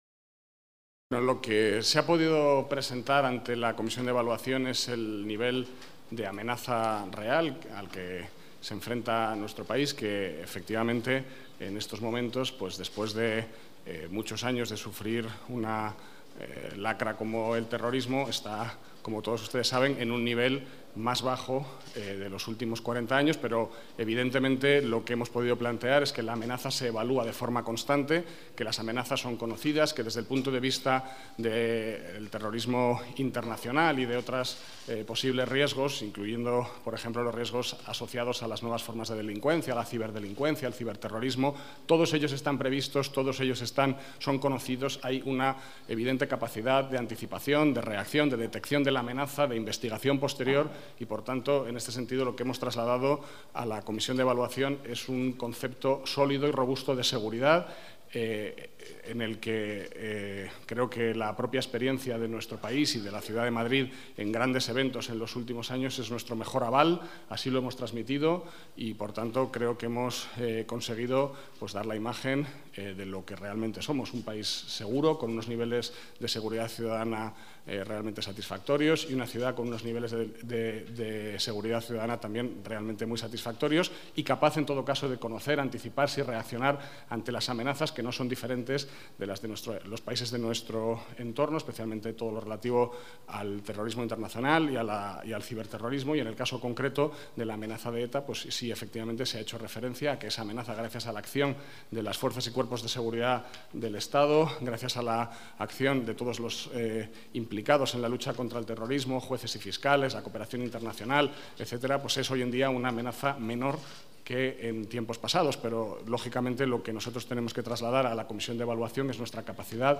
Nueva ventana:Declaraciones de Francisco Martínez Vázquez, secretario de Estado para la Seguridad: concepto sólido de seguridad